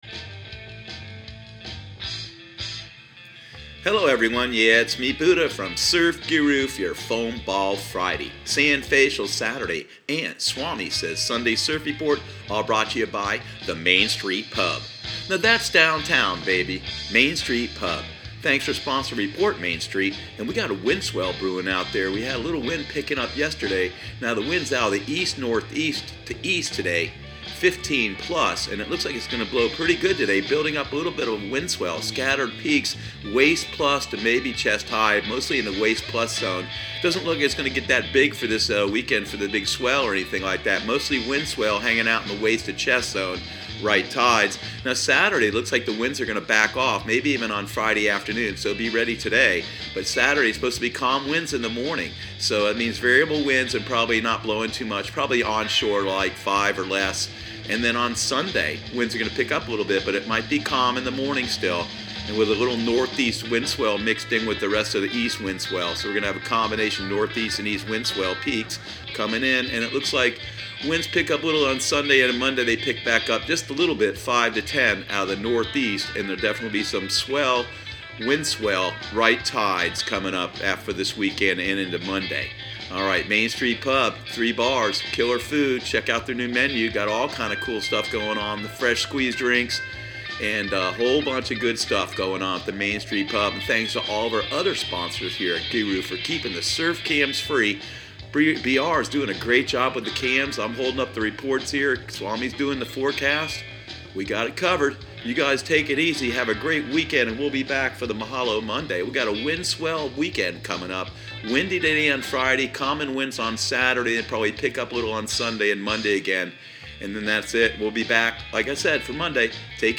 Surf Guru Surf Report and Forecast 02/01/2019 Audio surf report and surf forecast on February 01 for Central Florida and the Southeast.